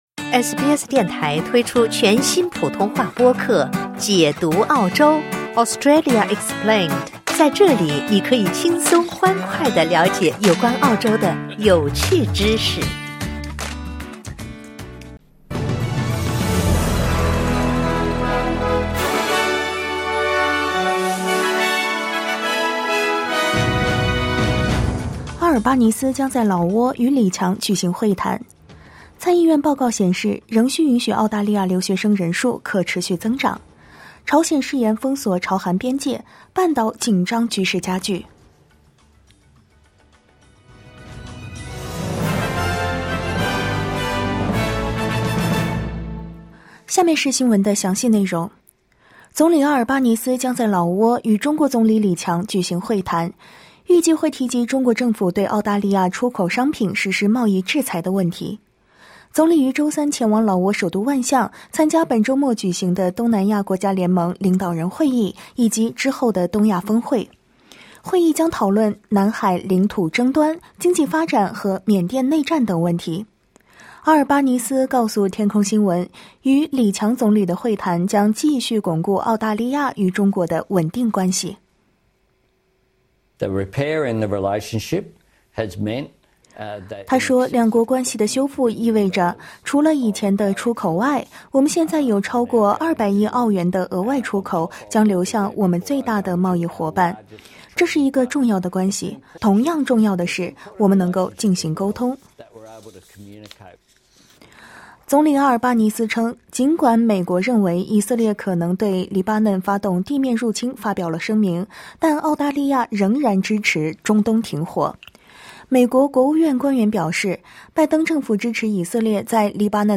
SBS早新闻（2024年10月10日）